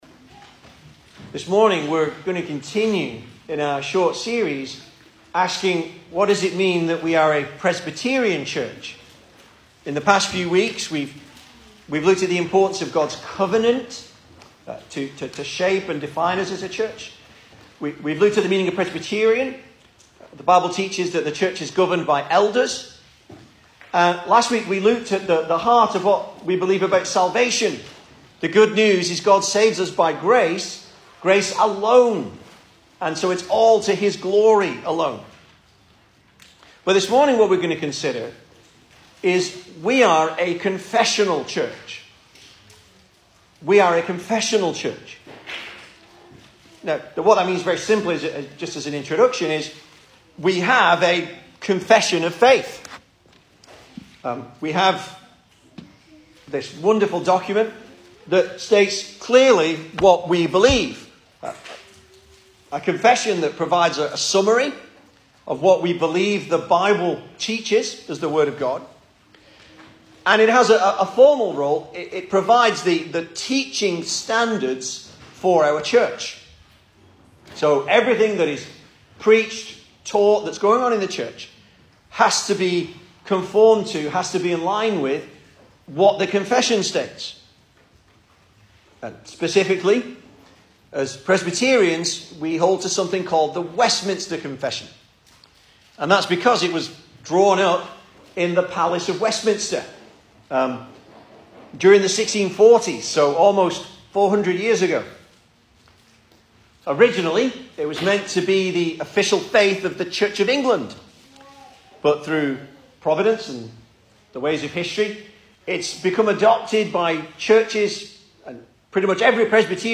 2022 Service Type: Sunday Morning Speaker